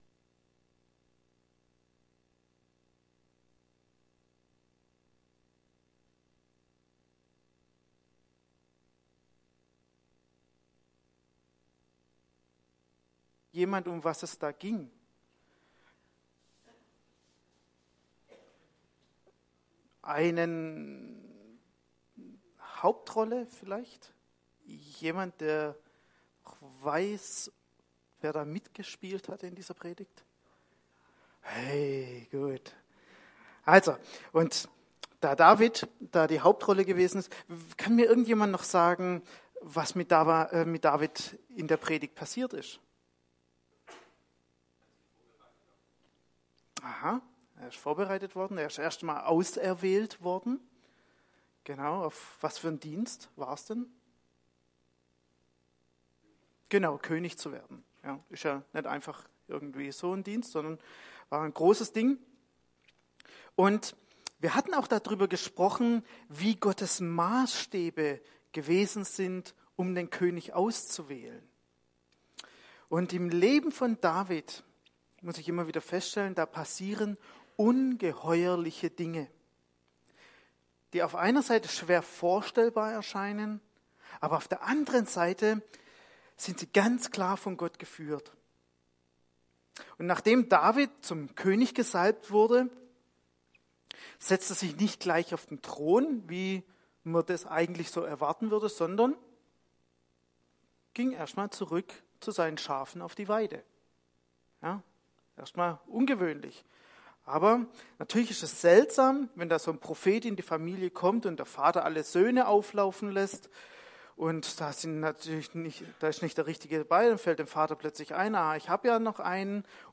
Predigt vom 4. März 2018 › ETG-Ludwigsburg